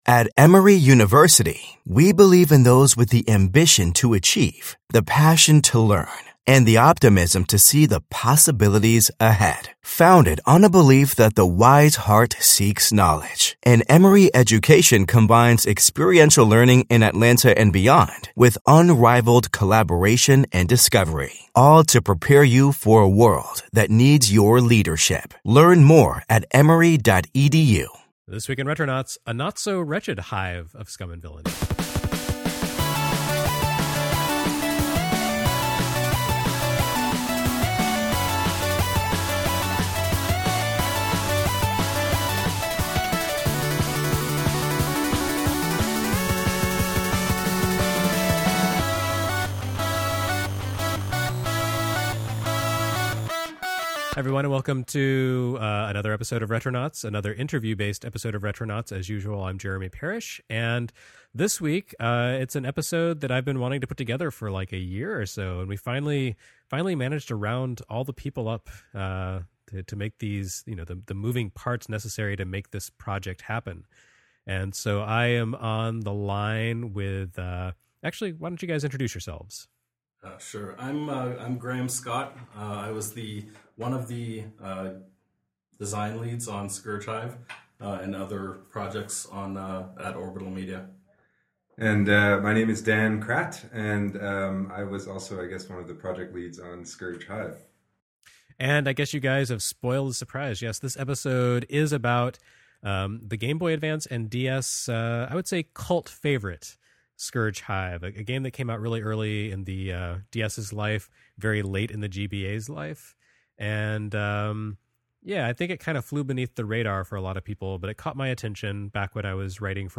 another interview based episode